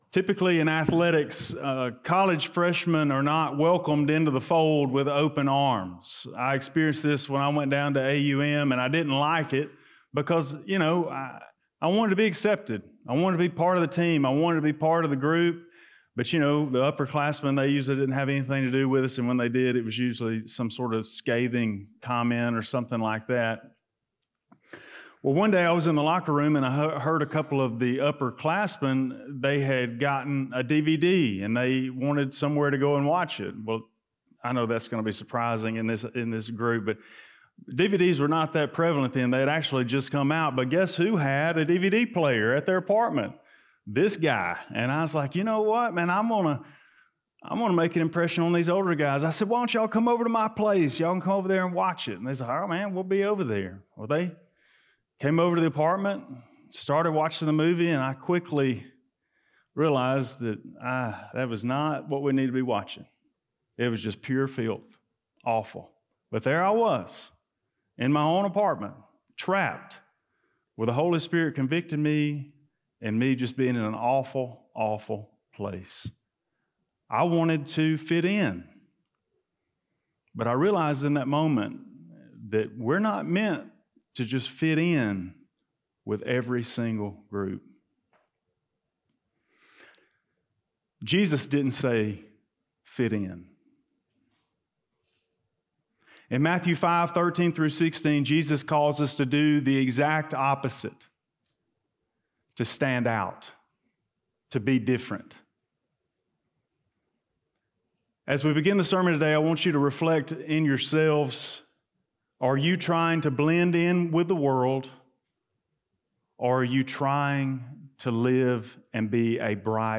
Blount Springs Baptist Church Sermons